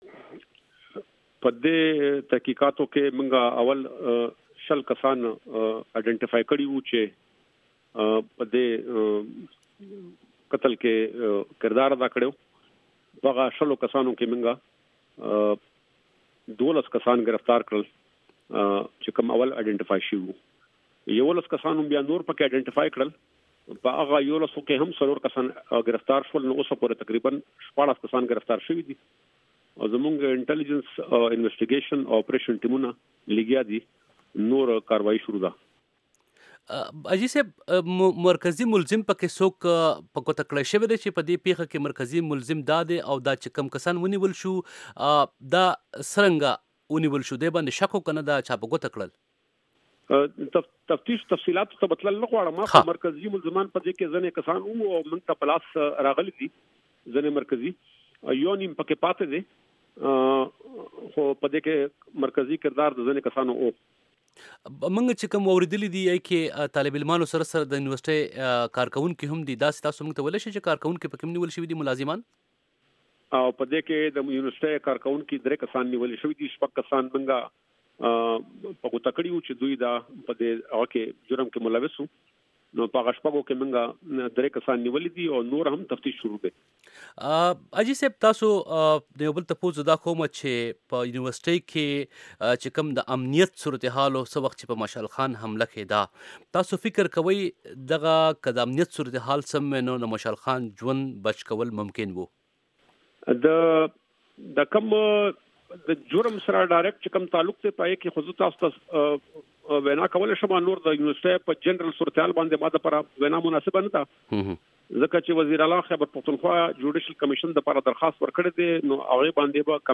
د خیبر پښتونخوا د پولیس مشر مرکه